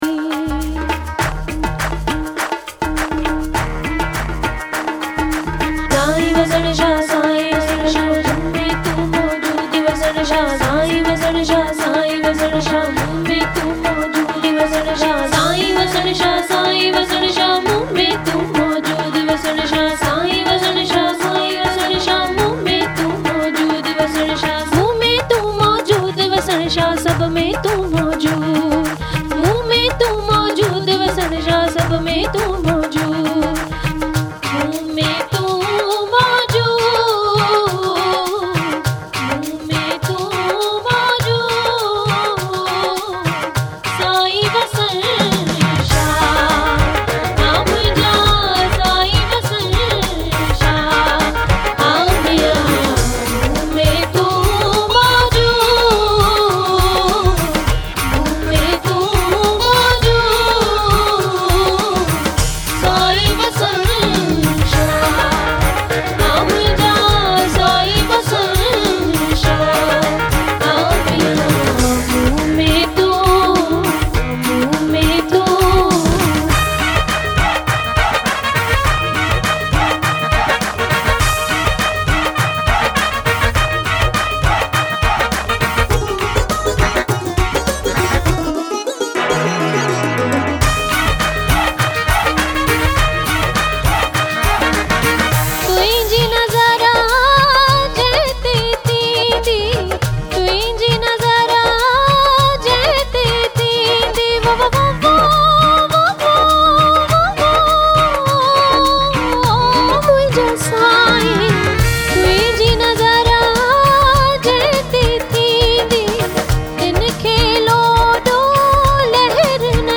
mesmerising folk and romantic songs
Sindhi songs